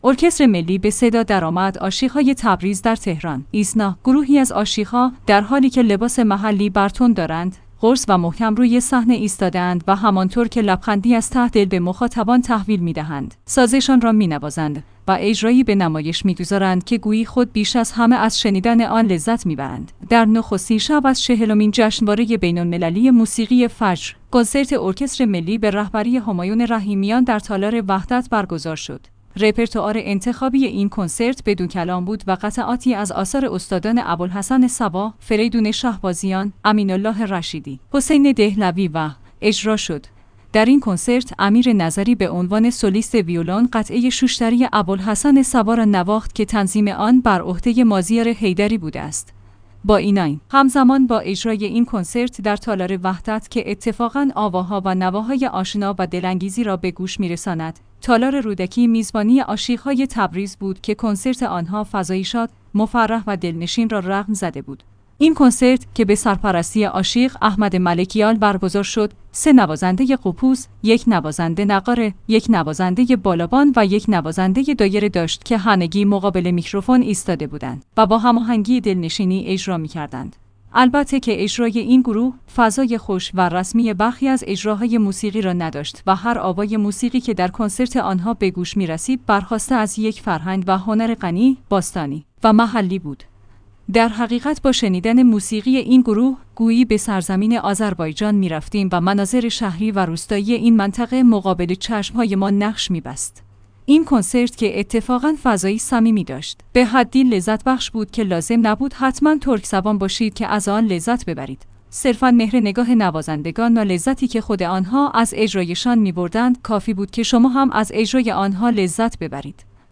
ایسنا/ گروهی از عاشیق‌ها، در حالی که لباس محلی بر تن دارند، قرص و محکم روی صحنه ایستاده‌اند و همانطور که لبخندی از ته دل به مخاطبان تحویل می‌دهند، سازشان را می‌نوازند و اجرایی به نمایش می‌گذارند که گویی خود بیش از همه از شنیدن آن لذت می‌برند.